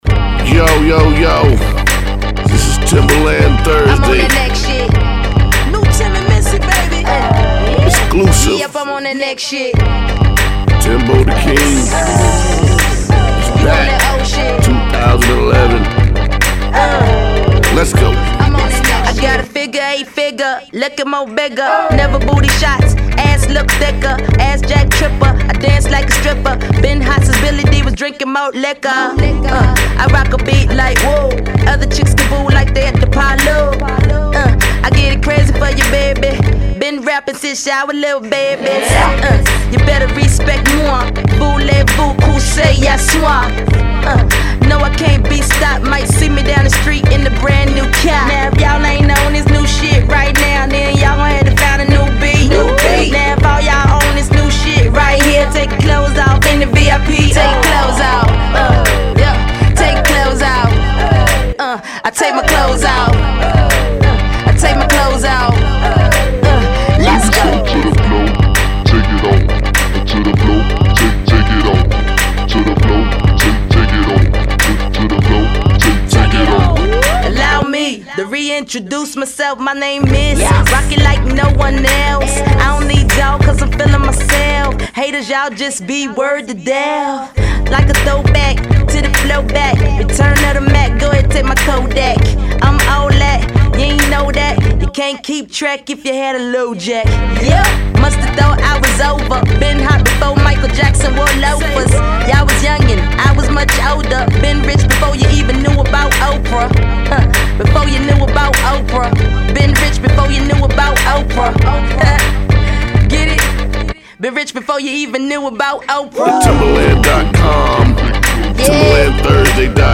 The frolicking club beat has an early to mid 2000s vibe.